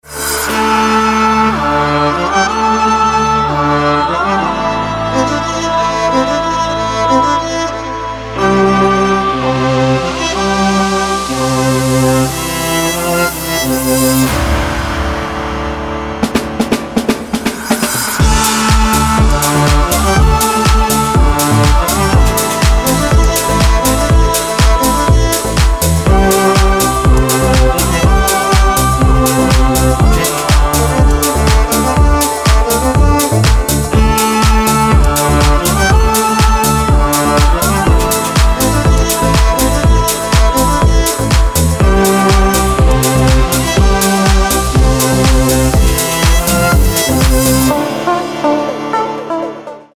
в стиле Deep House